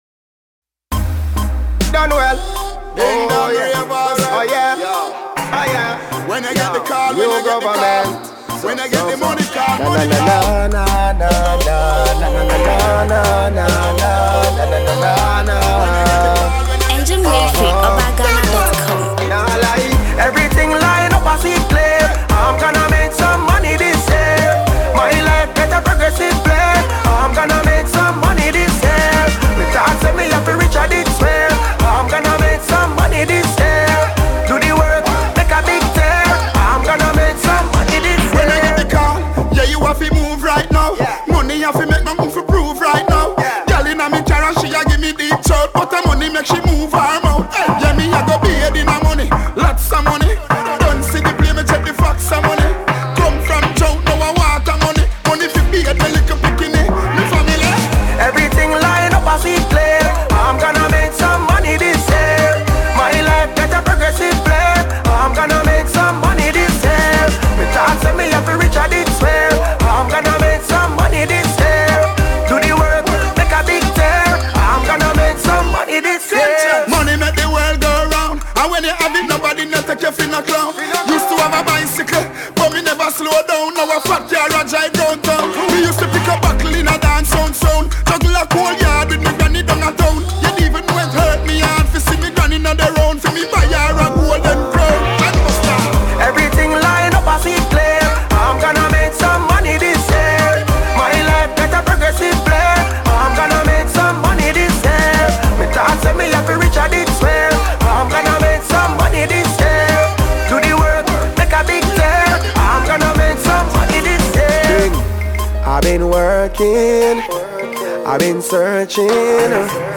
Reggae/Dancehall